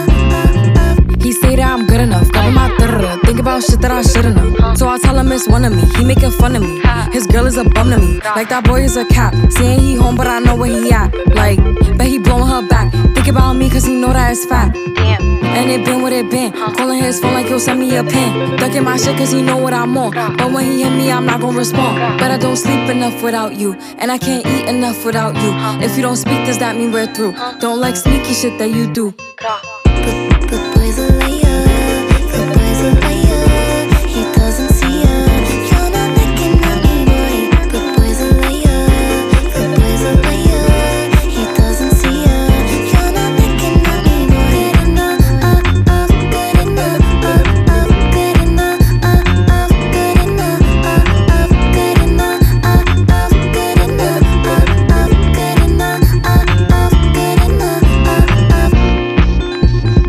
dance , pop music , rap music